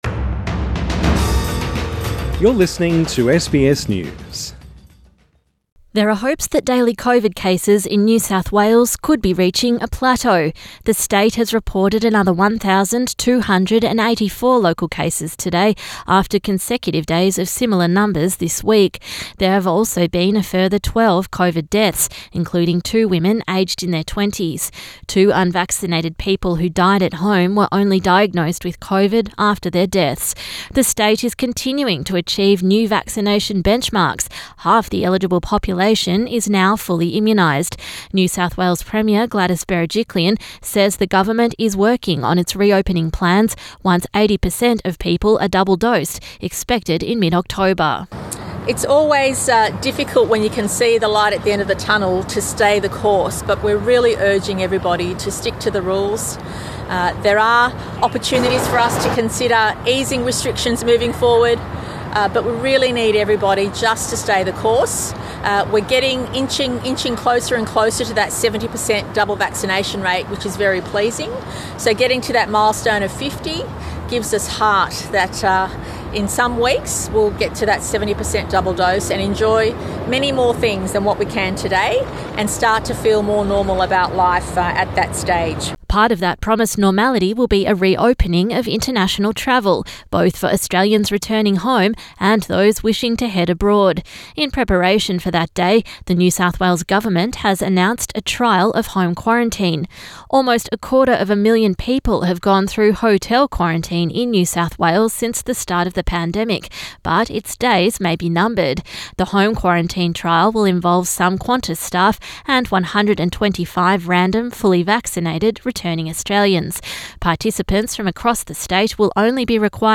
NSW Premier Gladys Berejiklian speaks to the media during a press conference in Sydney Source: AAP